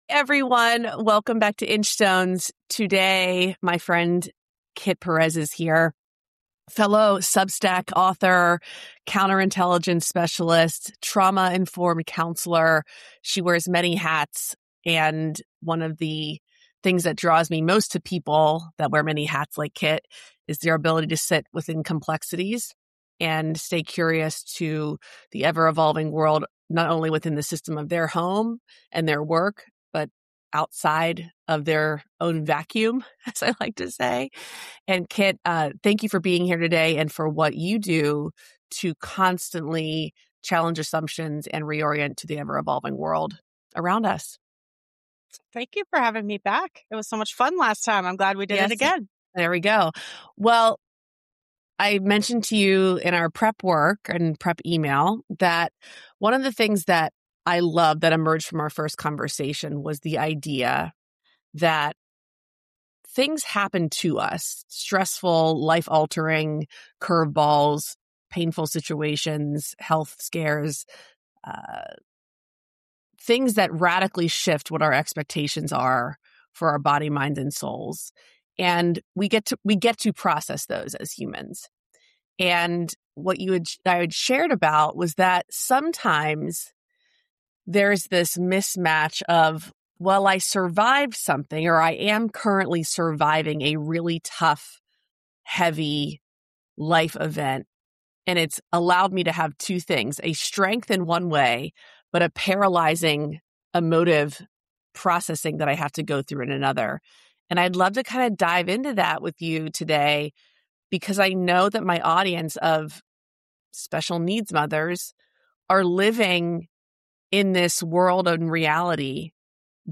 This conversation refuses simplification.